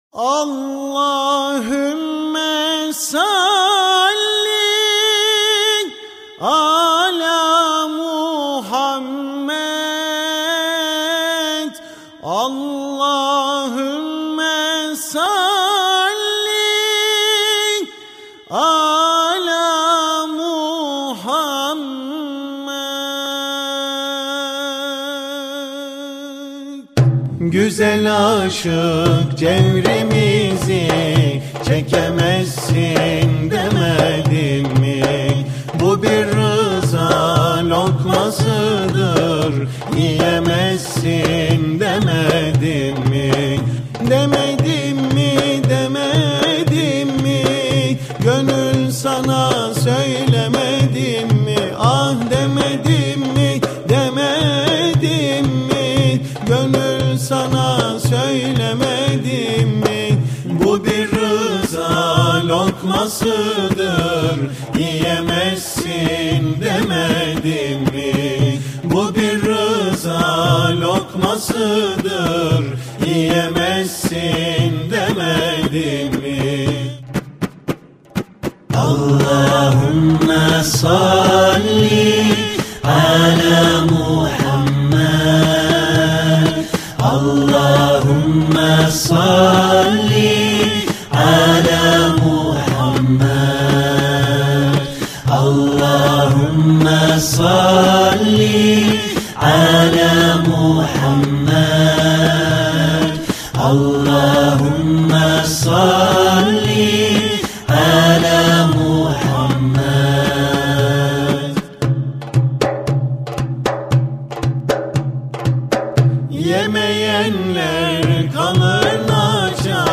who has achieved fame as a performer of nasheed songs.